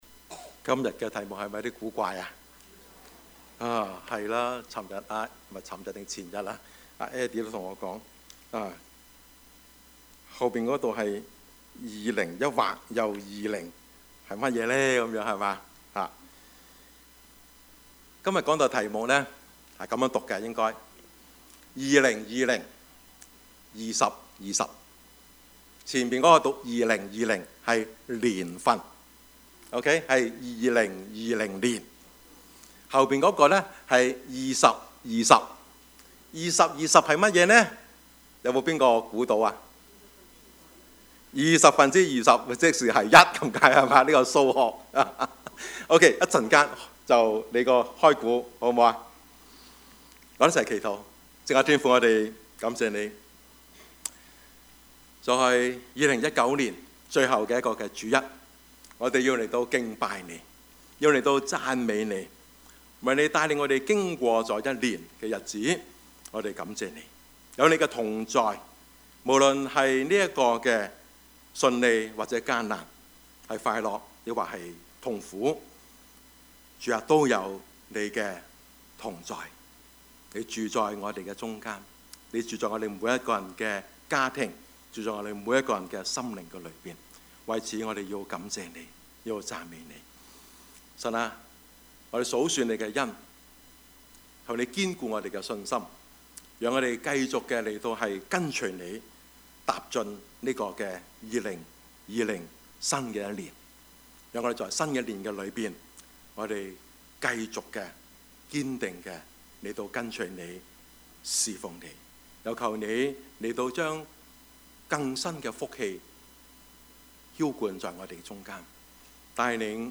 Service Type: 主日崇拜
Topics: 主日證道 « 聖誕與我何干?